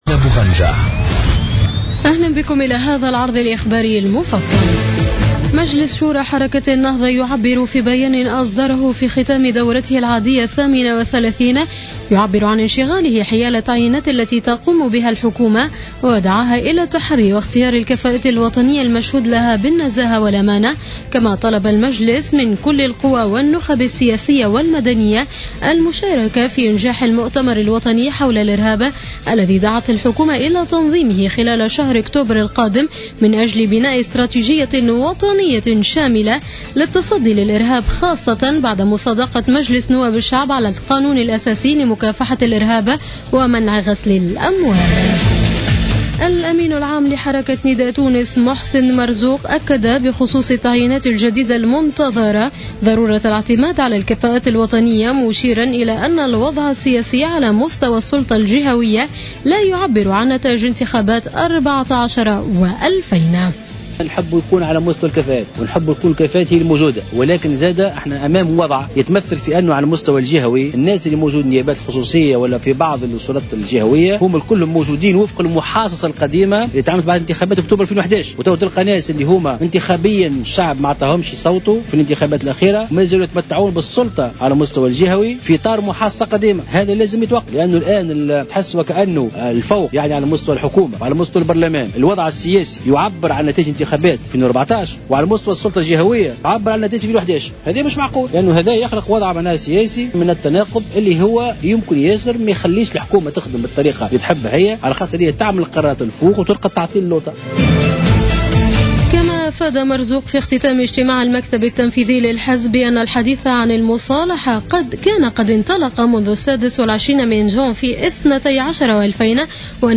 نشرة أخبار منتصف الليل ليوم الإثنين 3 أوت 2015